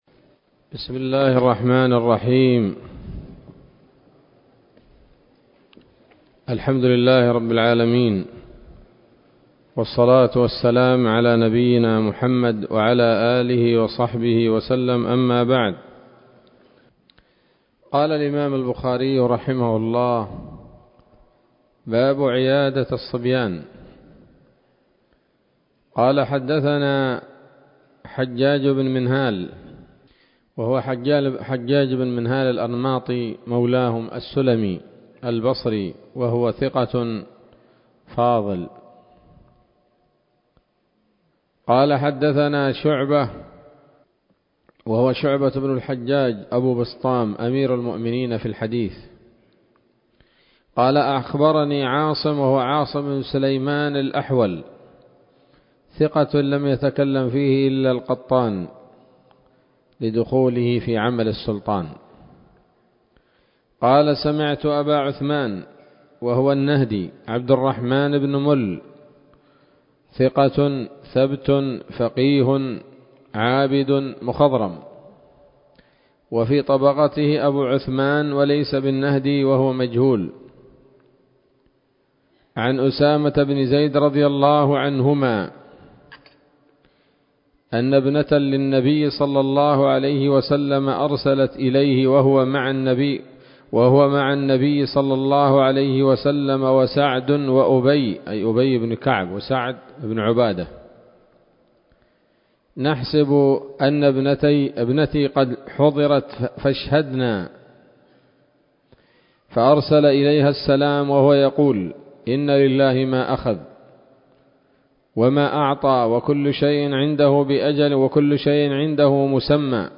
الدرس الثامن من كتاب المرضى من صحيح الإمام البخاري